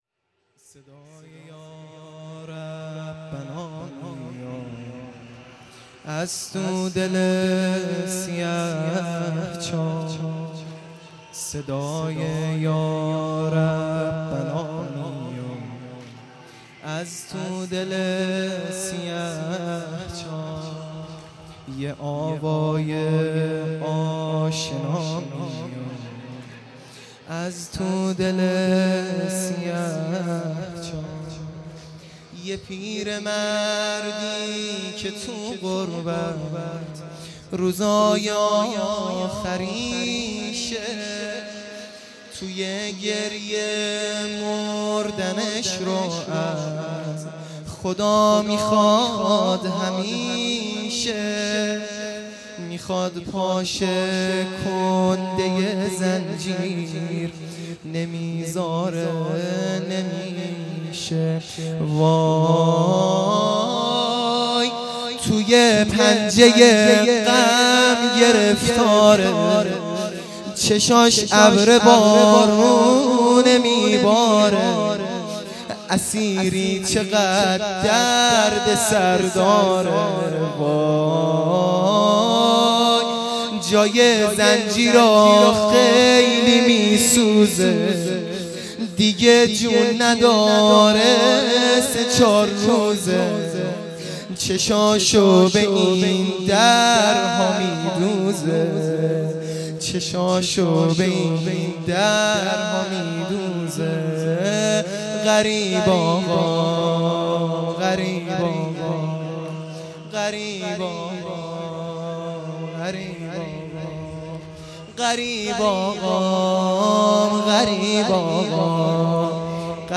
سنگین ا صدای یا ربنا میاد
تجمع عزاداران امام کاظم علیه السلام